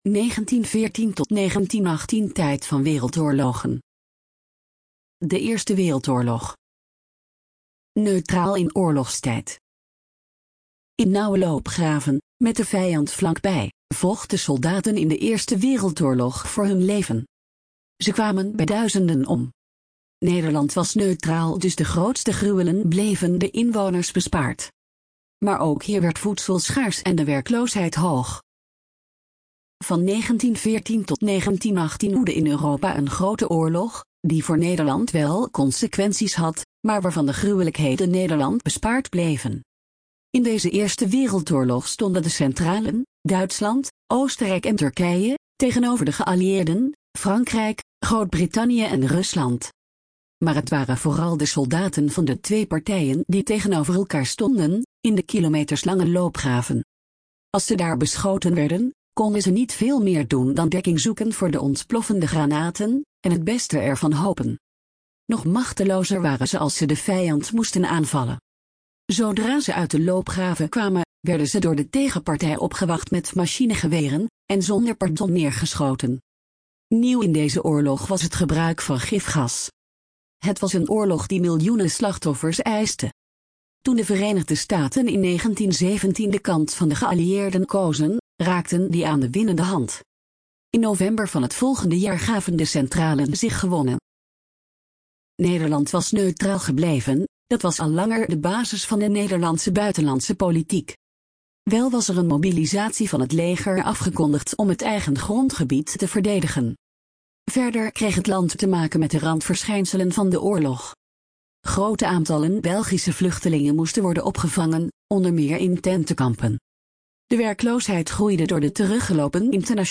[Automatische stem - Voz automática]